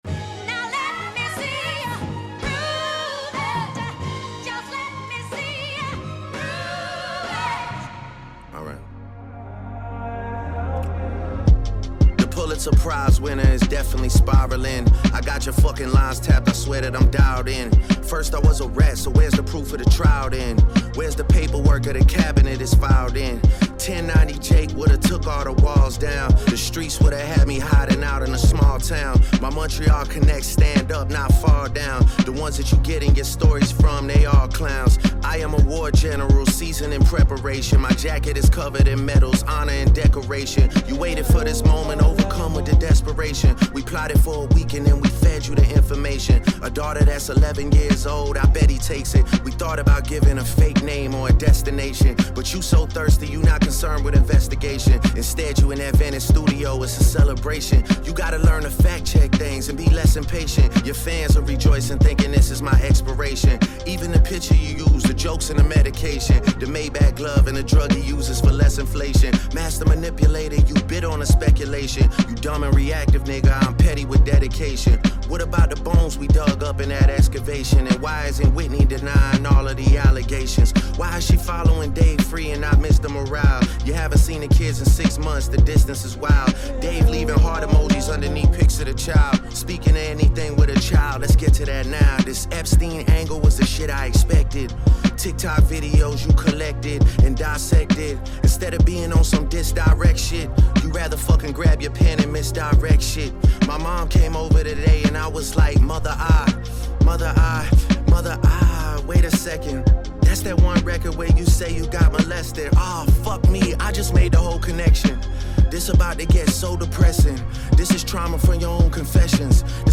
* Track aus Video extrahiert.